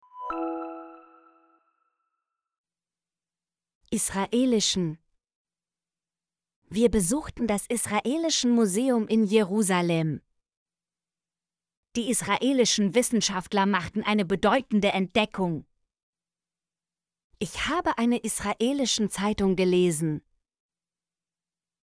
israelischen - Example Sentences & Pronunciation, German Frequency List